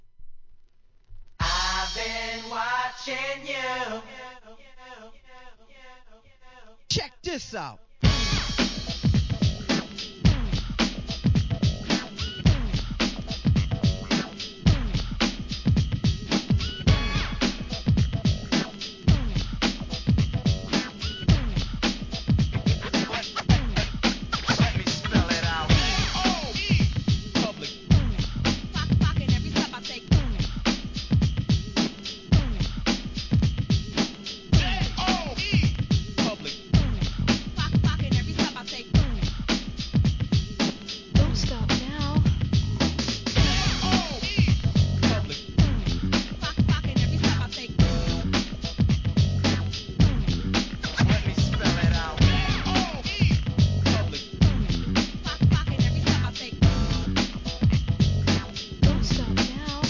HIP HOP/R&B
NEW JACK SWING